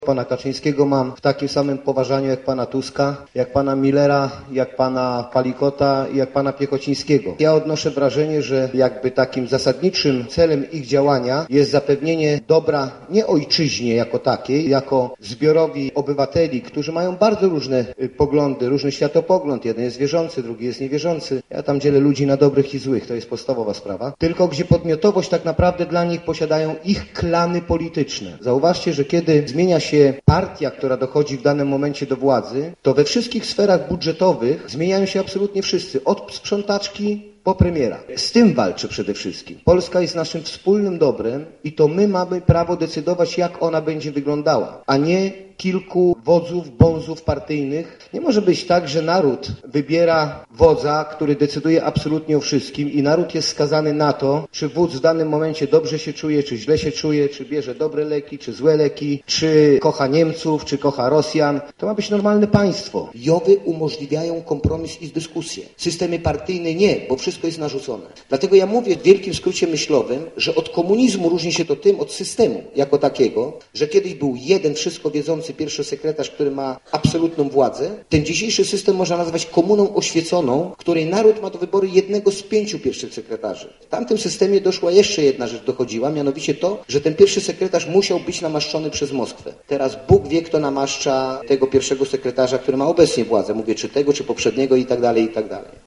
Przedpołudniowe spotkanie